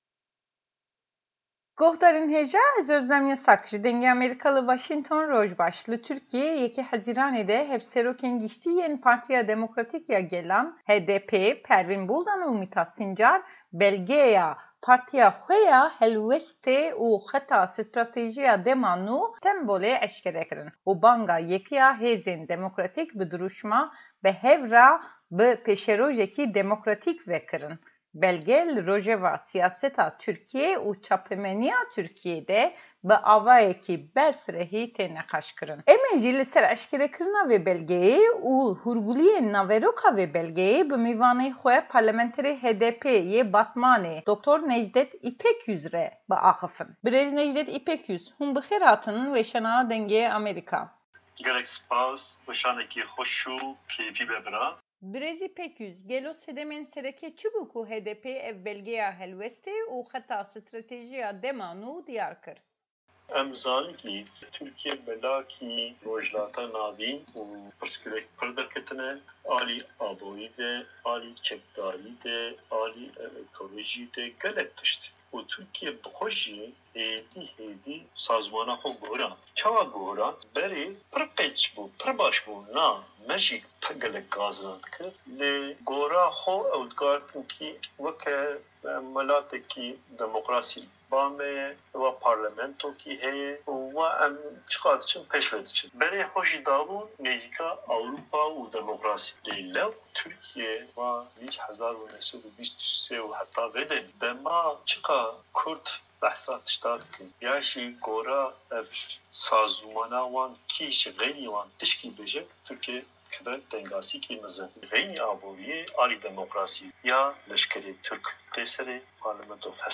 Heypeyvîna Parlementera HDP'ê ya Batmanê Nejdet Îpekyüz